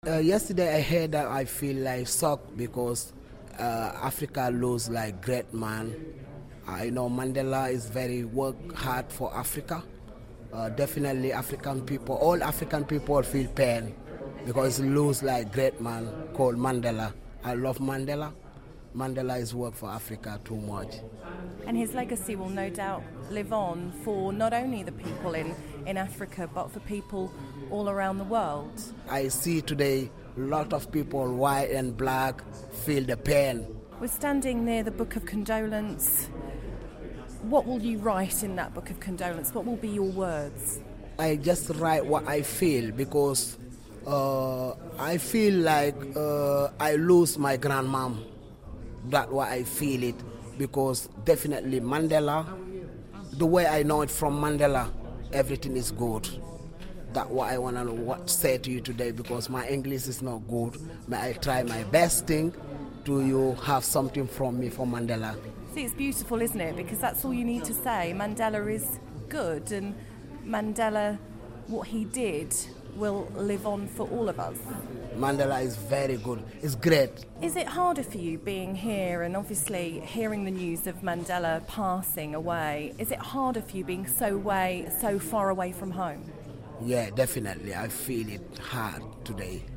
Watch his impromptu performance in tribute to Nelson Mandela at Liverpool Town Hall.